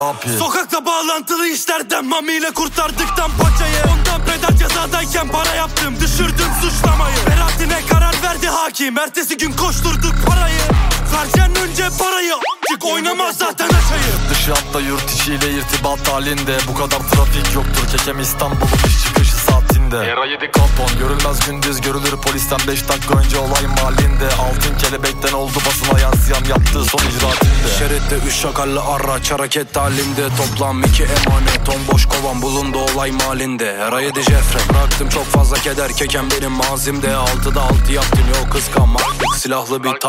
Kategori Rap